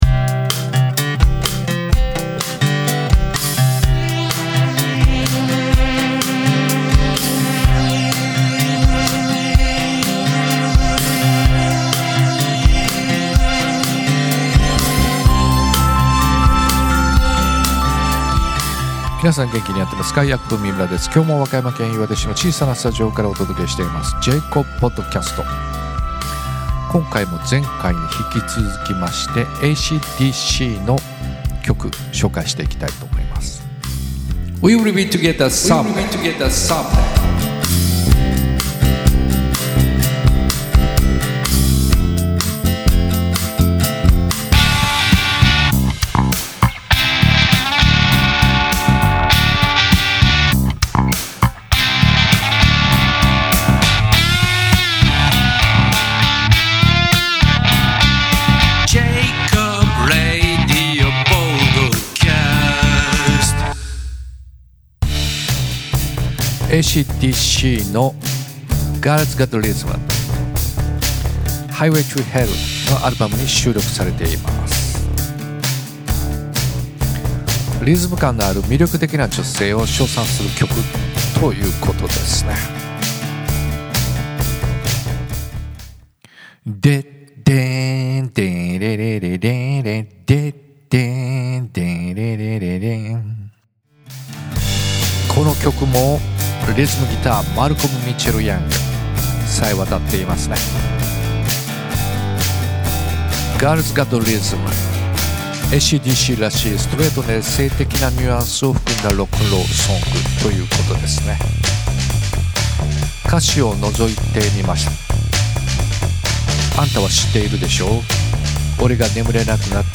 ・エレキギター
・キーボード（keys、ベース）
・アルトサクソフォン
・ボーカル
・ドラム（GarageBand）